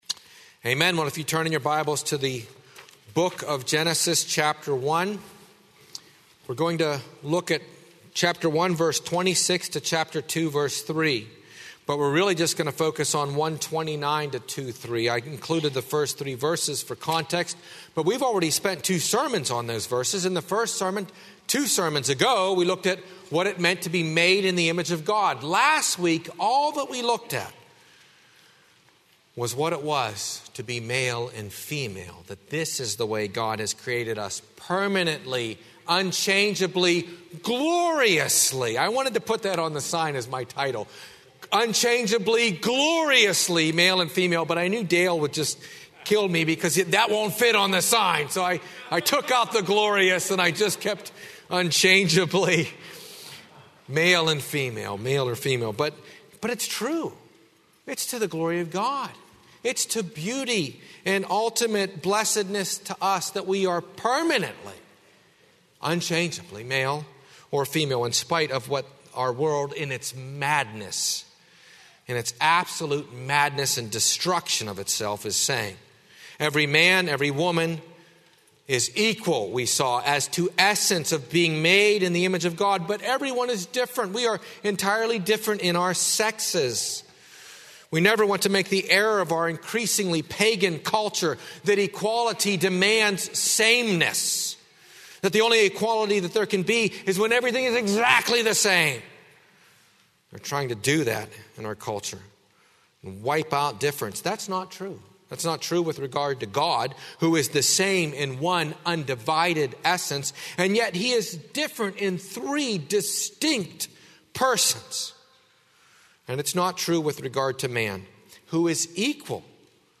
00:00 Download Copy link Sermon Text Genesis 1:26–2:3